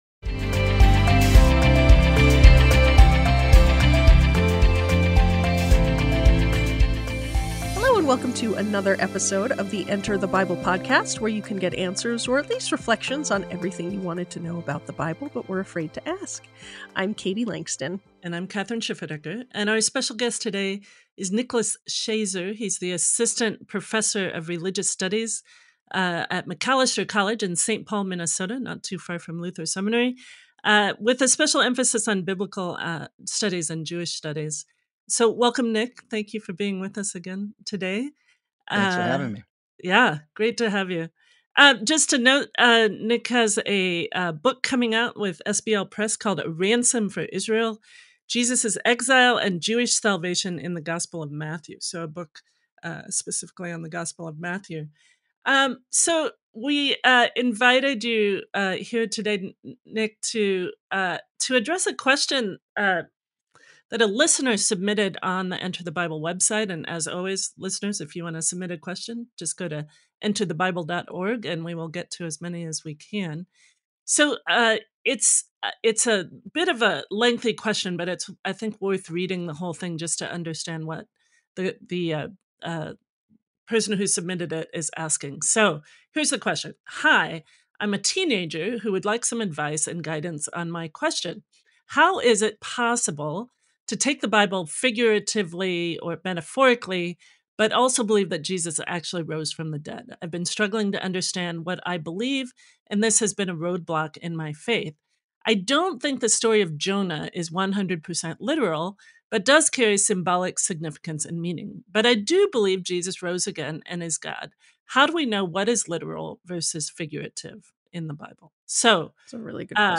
co-hosts